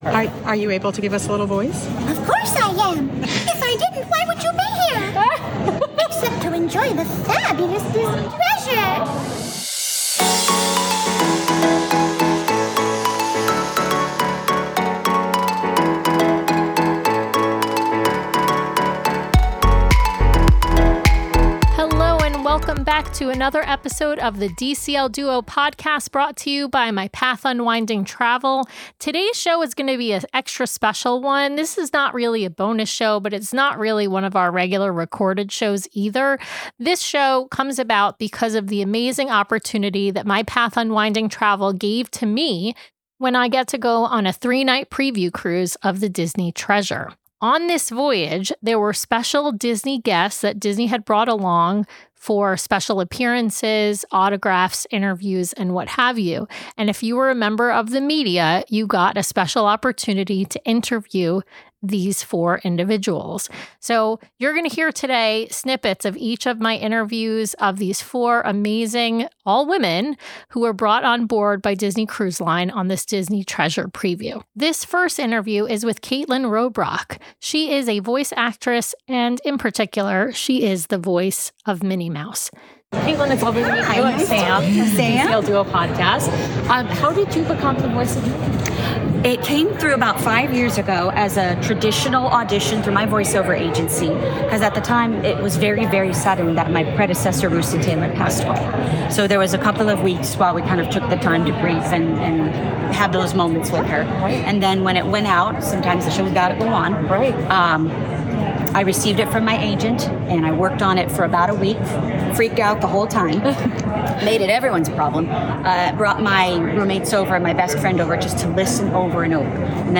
1 Ep. 503 - Bonus - Special Guests: Special Interviews with Disney Cast from Aboard the Treasure 24:07